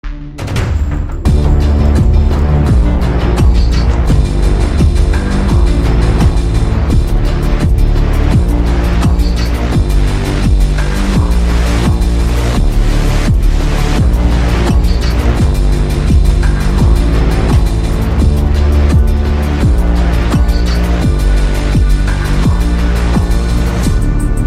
آهنگ زنگ بی کلام